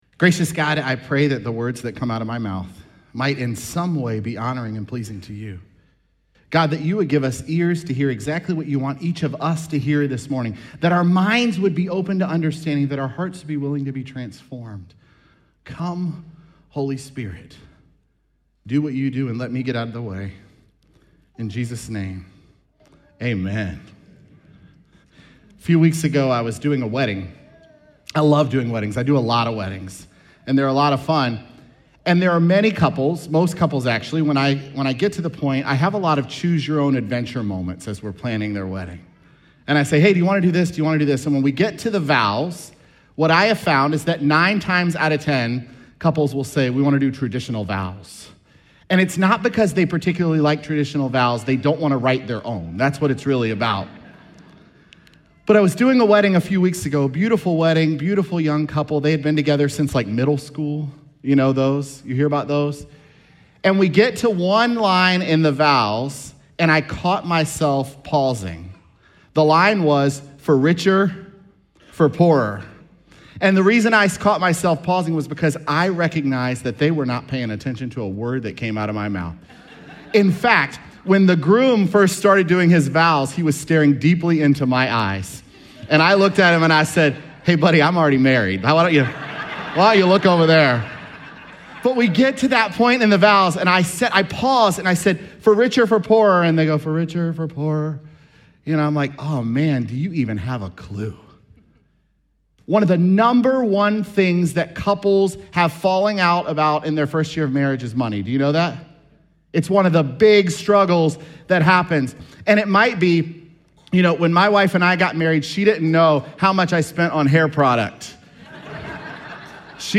Oct13SermonPodcast.mp3